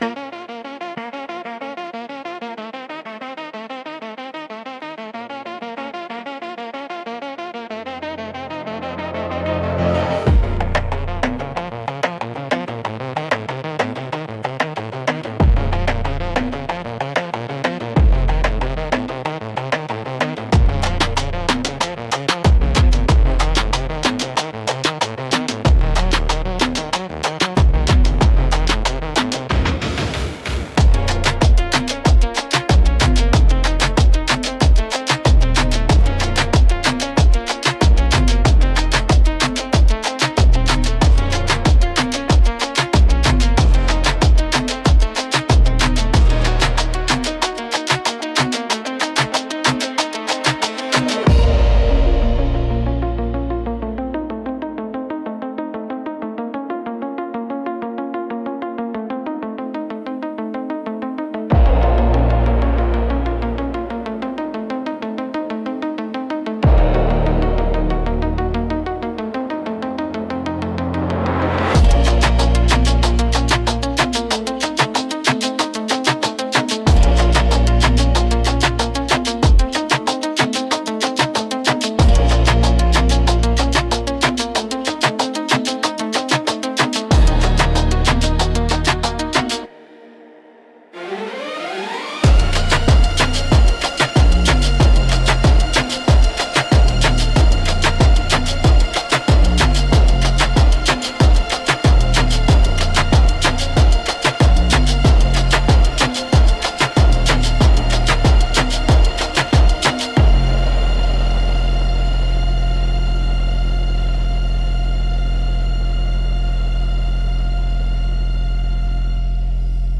• Defined the audio direction: dark synthwave with cinematic trailer build-ups, heavy bass, and electronic accents.